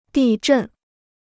地震 (dì zhèn): earthquake.